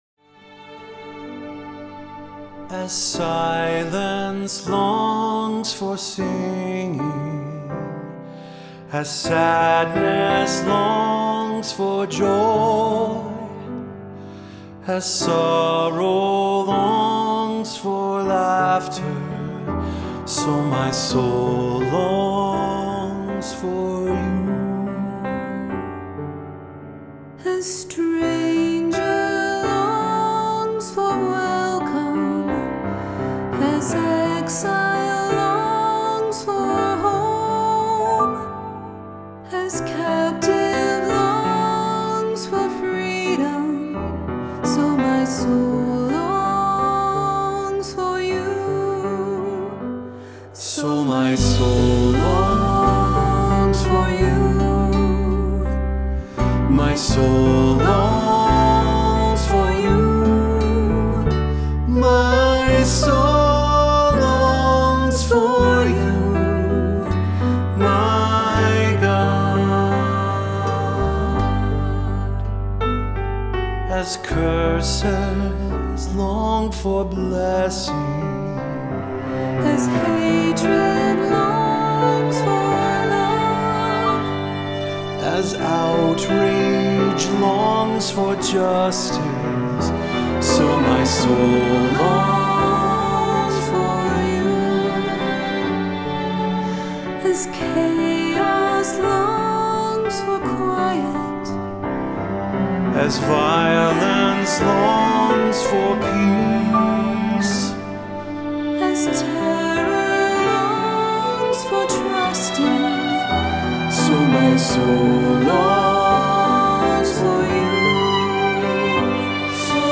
Voicing: SATB, assembly, cantor